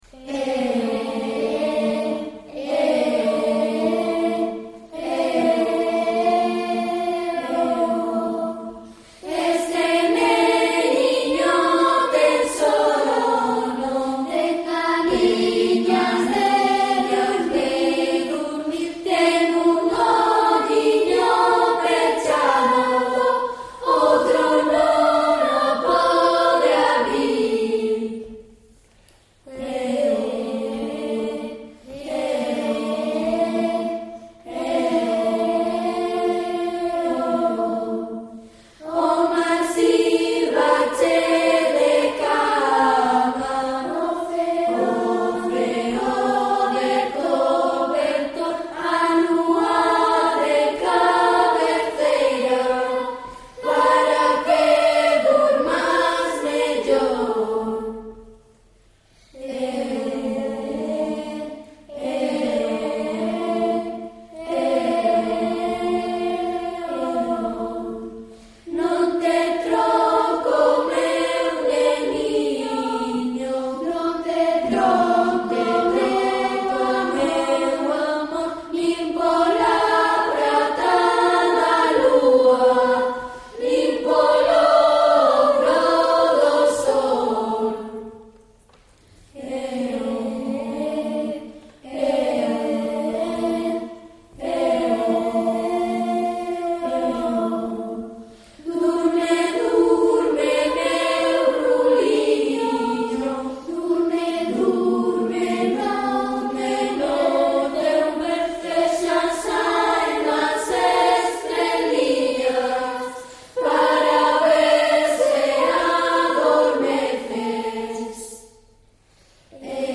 " Le berceau" por Berthe Morisot, 1872 " Durme, durme, meu ruliño " é un canto de arrolo de Sacos (Pontevedra) Presentámolo aquí como arranxo a dúas voces, unha grave e outra aguda.